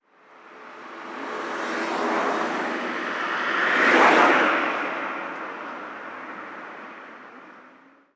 Coche pasando a velocidad normal 1
coche
Sonidos: Transportes